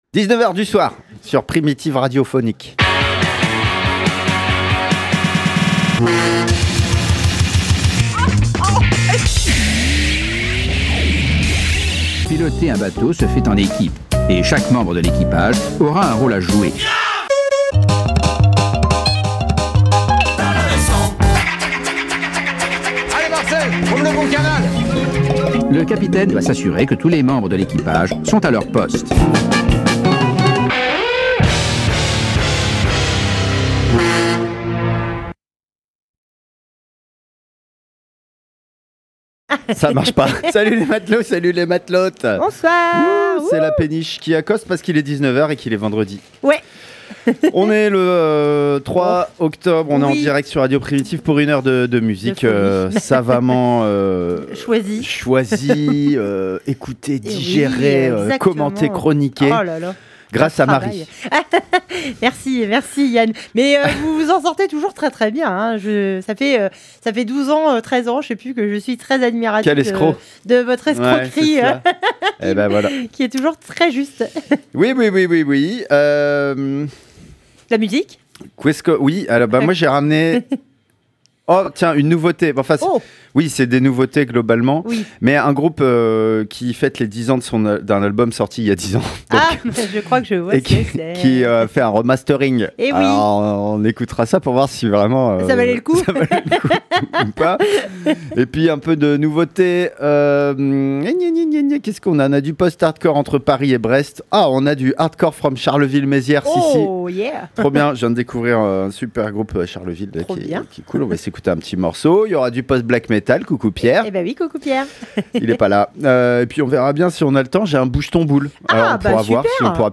🎧 Péniche 15x03 - Péniche radio show